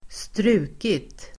Uttal: [²str'u:kit]